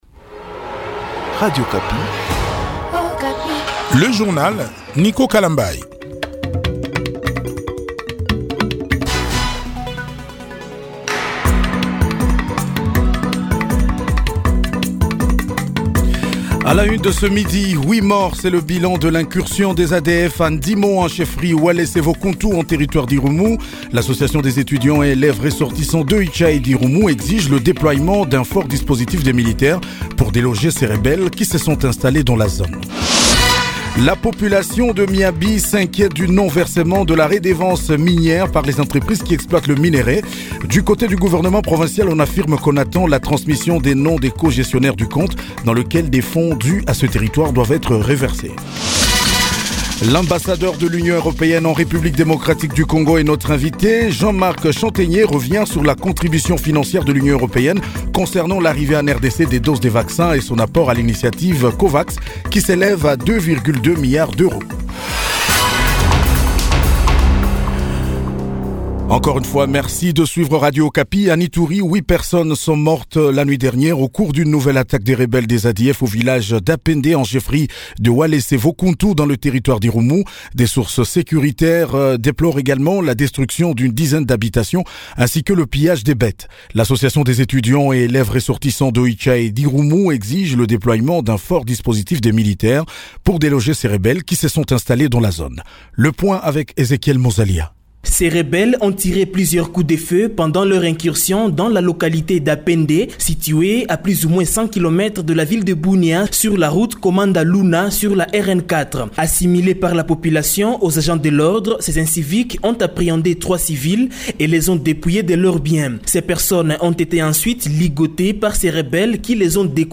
JOURNAL DU MARDI 09 MARS 2021 12H00